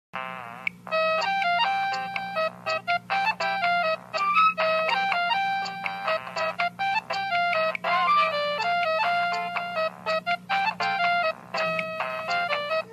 nokia-ringtone-arabic-1-mp3cut.mp3